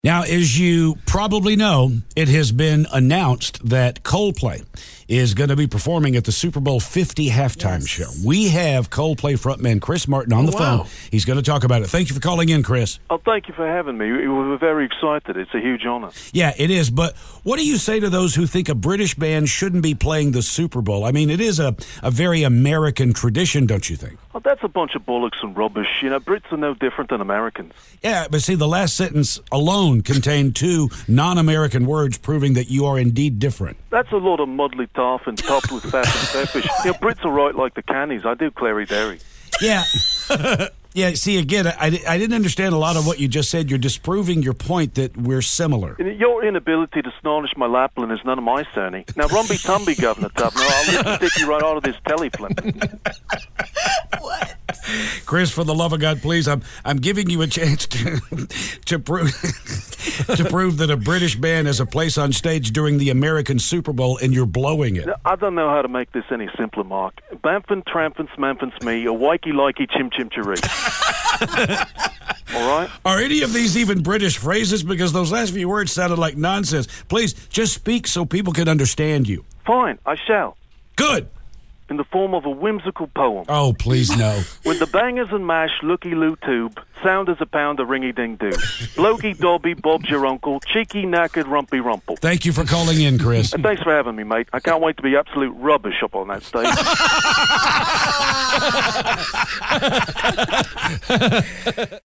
Coldplay's Chris Martin" Calls The Show